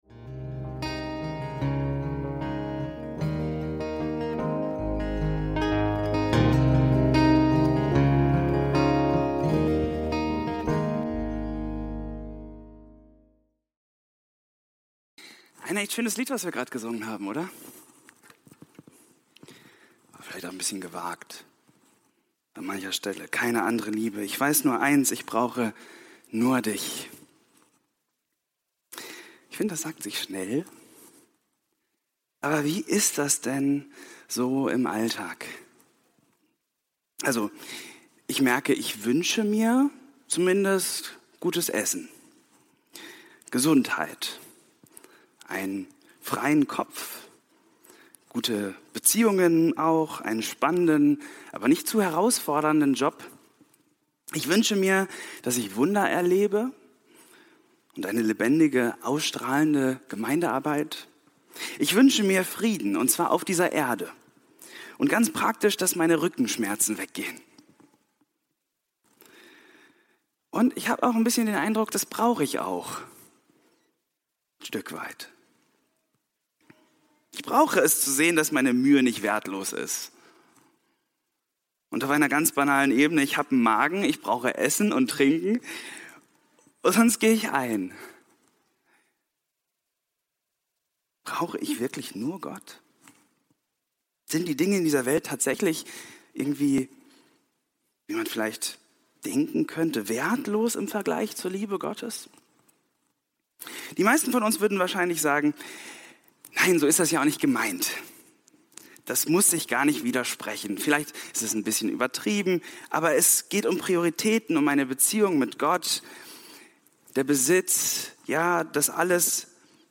Matthäus 6, 19–24 – Predigt vom 02.11.2025